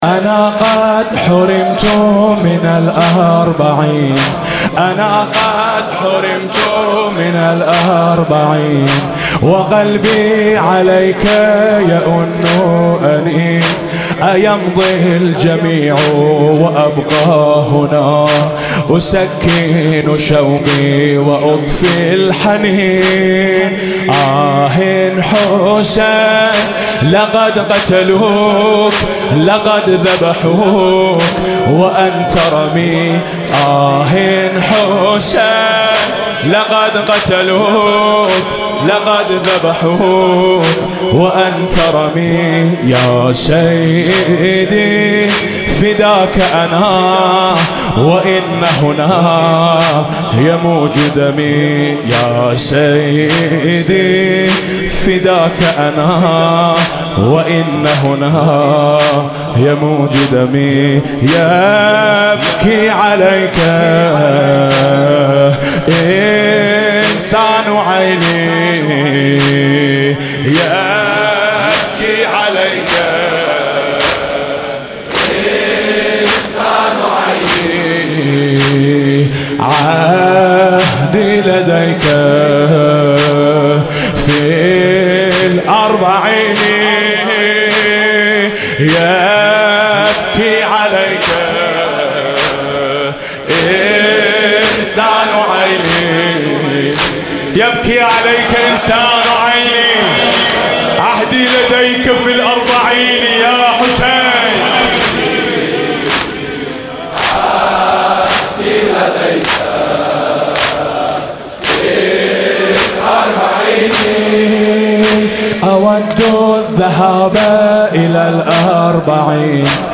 لطميات-الكوثر